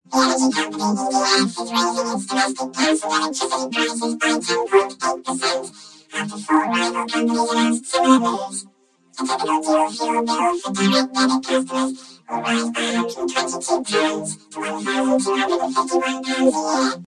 More vocoder examples
Vocoded with synth chords and rich stereo chorus added afterwards.